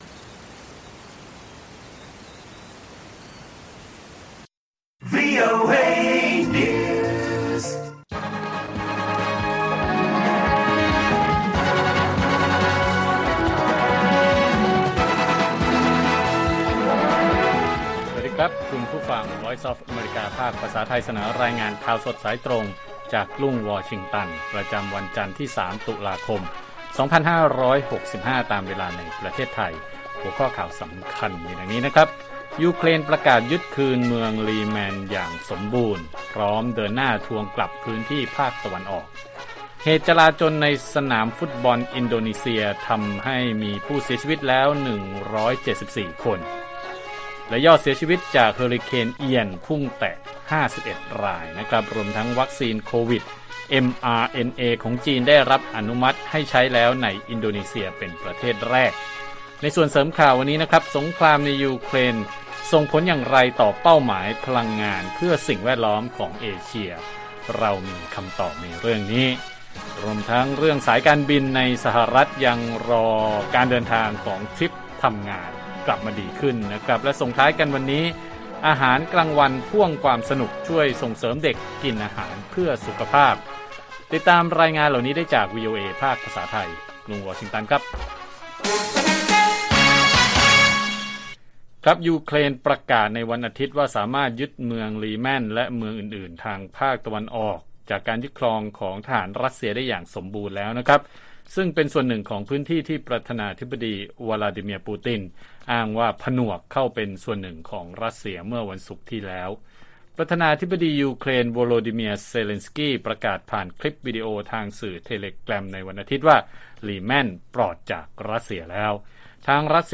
ข่าวสดสายตรงจากวีโอเอไทย จันทร์ ที่ 3 ต.ค. 65